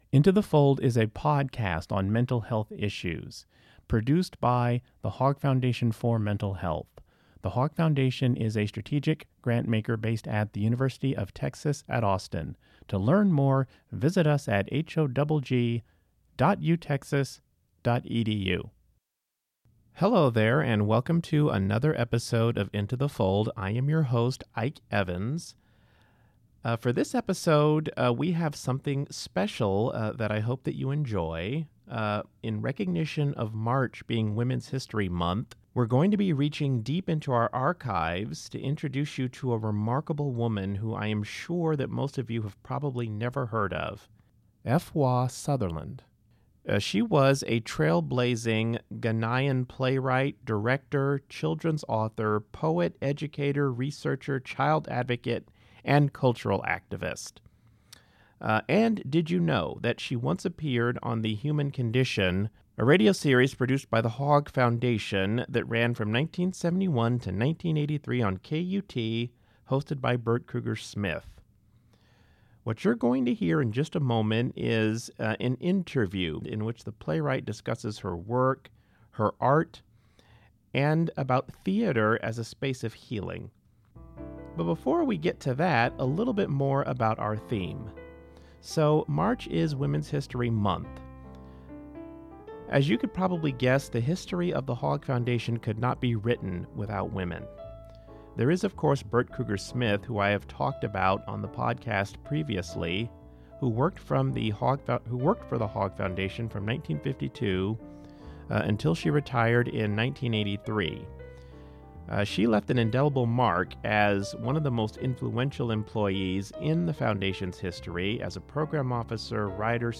In honor of Women’s History Month, this episode of Into the Fold features the voices of three extraordinary women in the Hogg Foundation’s history.